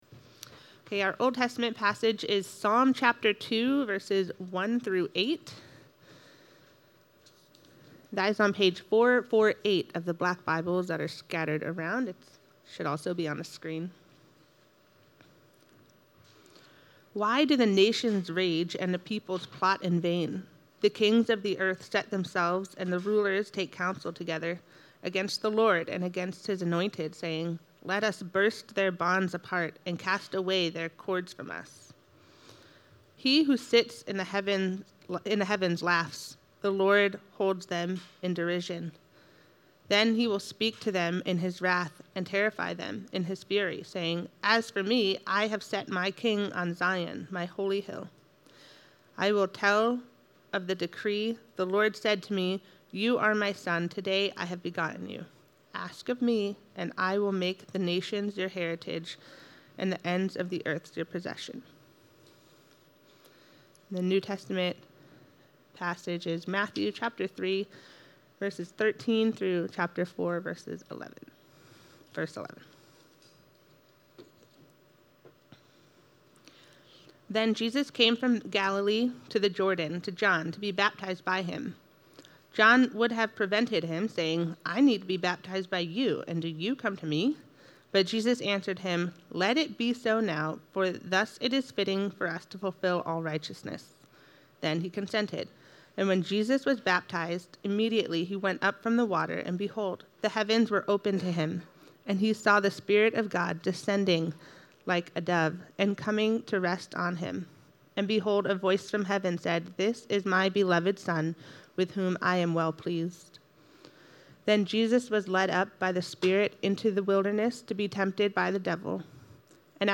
Sermons | Christ Presbyterian Church